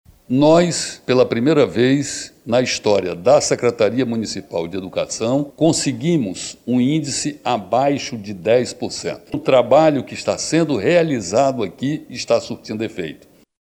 Esta é a primeira vez que o índice ficou abaixo de 10%, como destaca o secretário municipal de Educação, Pauderney Avelino.
Sonora-Pauderney-Avelino-Secretario-da-Semed.mp3